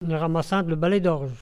Langue Maraîchin
Locution ( parler, expression, langue,... )